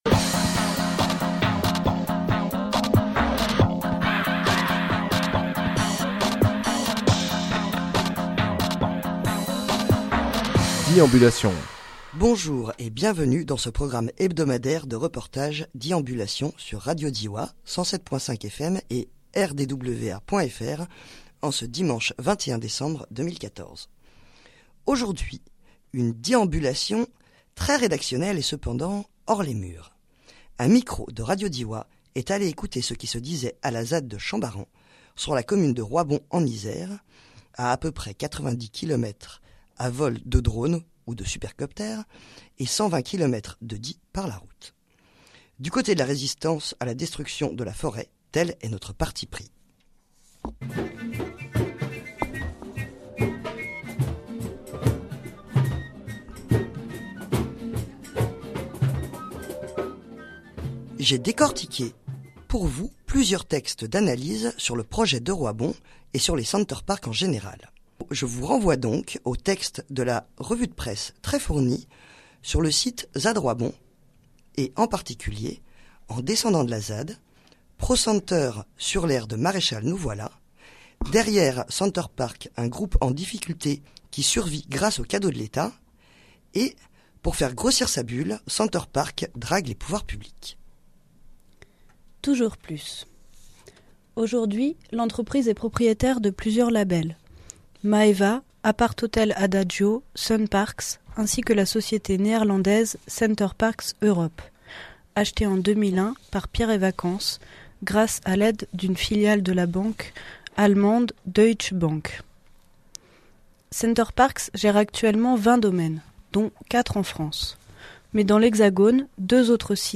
Emission - Reportages À la ZAD des Chambarans, décembre 2014 Publié le 21 décembre 2014 Partager sur… Télécharger en MP3 Un microphone de Radio Diois est allée écouter ce qui se passait sur cette ZAD à Roybon – Isère autour du WE dernier, du coté de la résistance, tel est notre parti-pris!